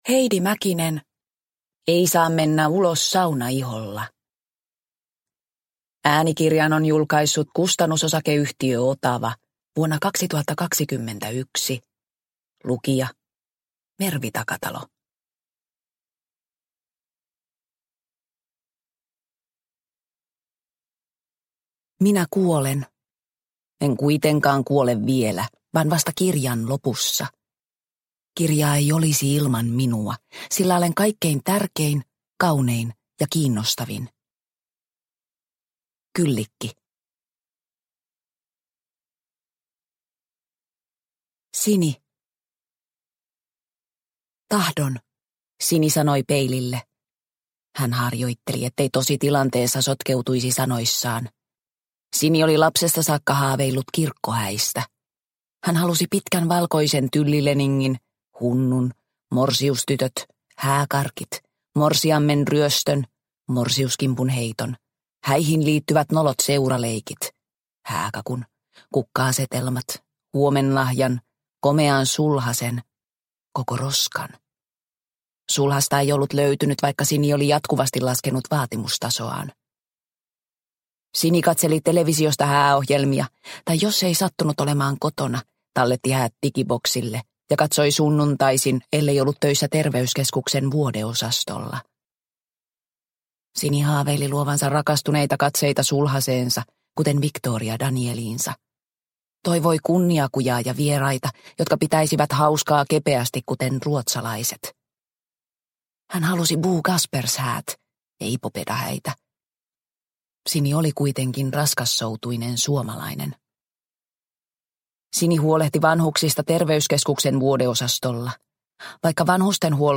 Ei saa mennä ulos saunaiholla – Ljudbok